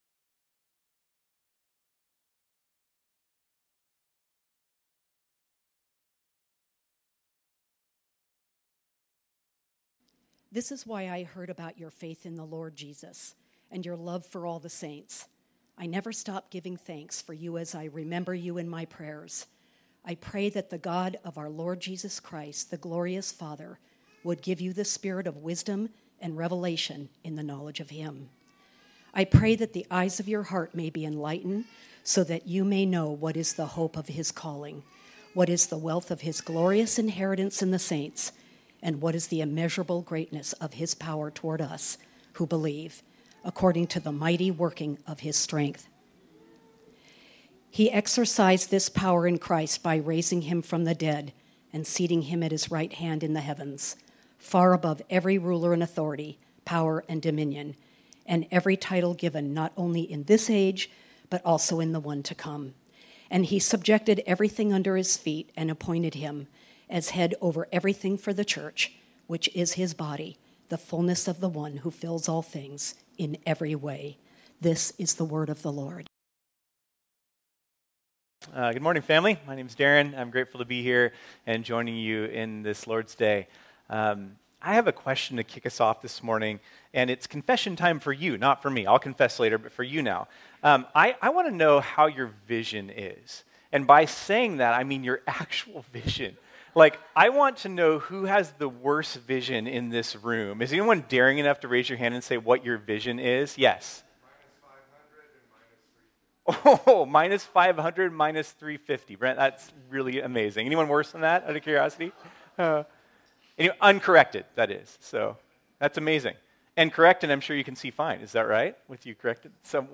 This sermon was originally preached on Sunday, September 17, 2023.